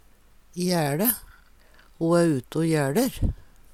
jæLe - Numedalsmål (en-US)